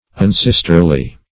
unsisterly - definition of unsisterly - synonyms, pronunciation, spelling from Free Dictionary
Unsisterly \Un*sis"ter*ly\